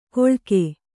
♪ koḷke